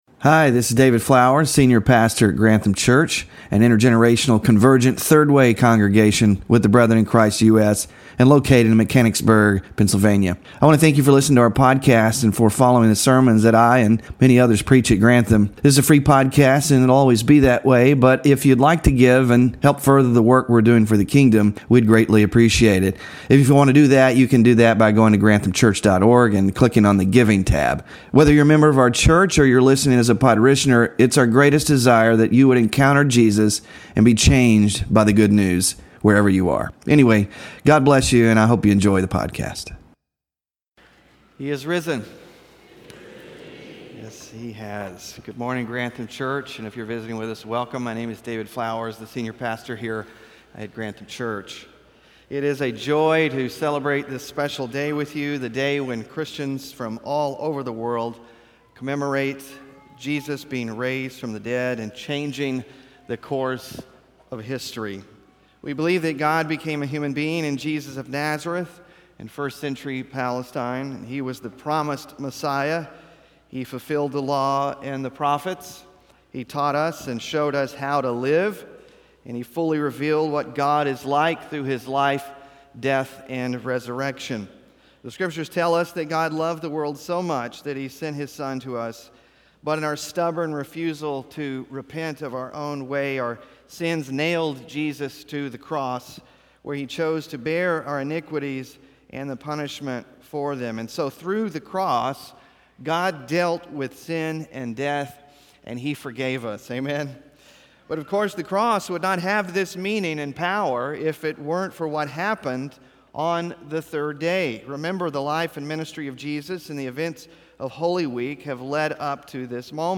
WORSHIP RESOURCES THE CHRISTIAN HOPE IN RESURRECTION SERMON SLIDES (4-20-25) SMALL GROUP QUESTIONS (4-20-25) BULLETIN including BAPTISM TESTIMONIALS (4-20-25)